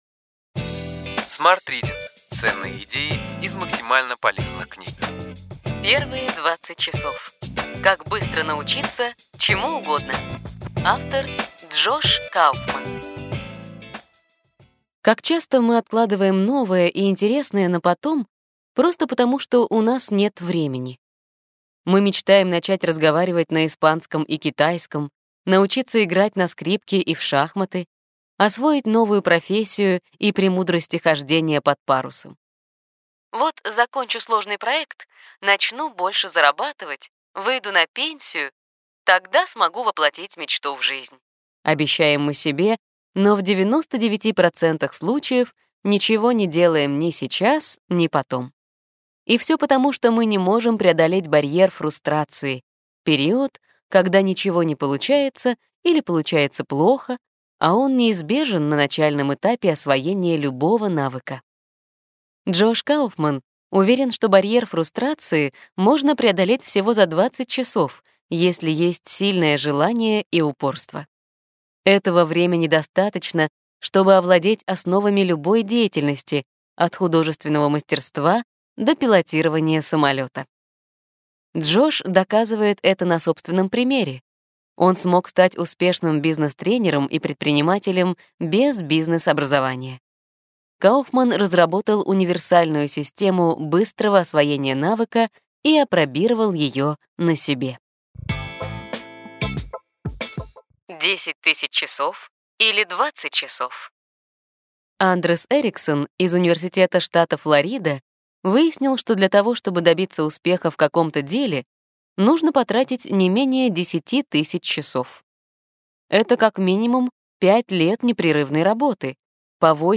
Аудиокнига Ключевые идеи книги: Первые 20 часов. Как быстро научиться чему угодно. Джош Кауфман | Библиотека аудиокниг